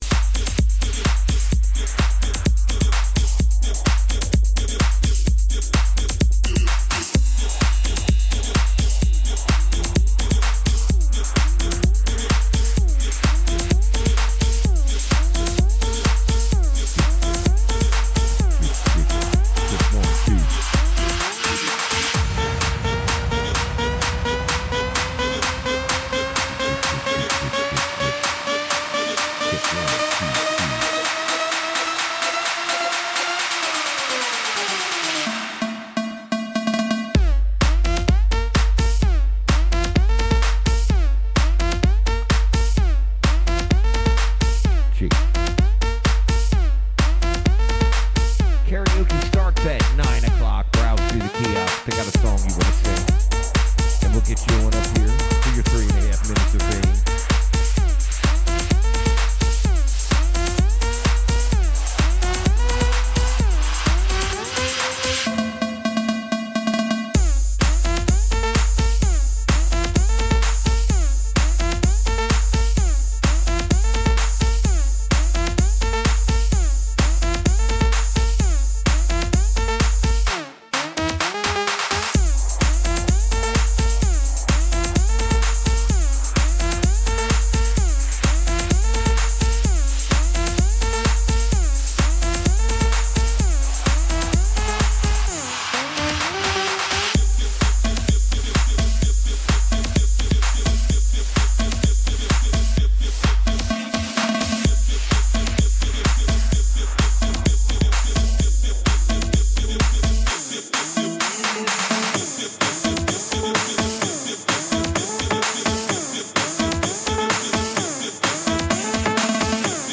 Mile High Karaoke Live Wednesday through Saturday 9-1 Broadways Shot Spot